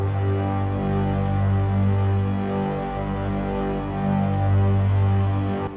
piano-sounds-dev
g3.wav